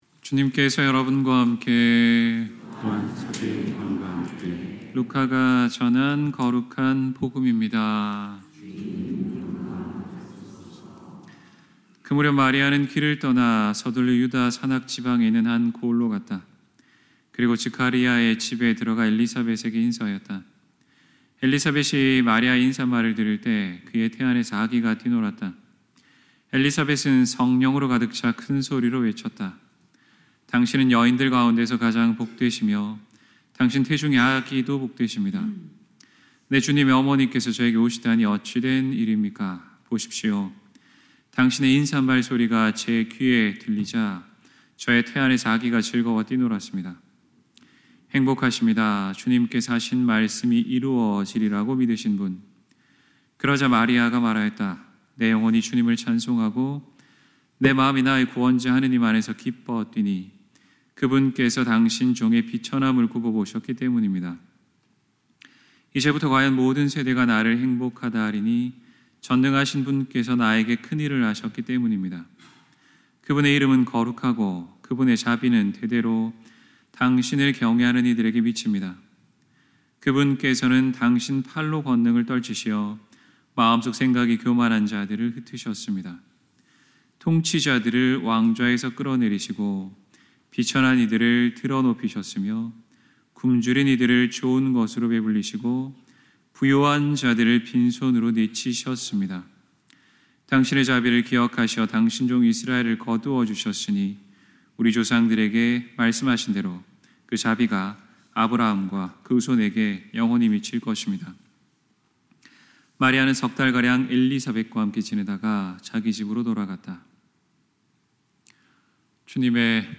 2024년 8월 11일 성모 승천 대축일 경축이동(연중 제19주일) 신부님 강론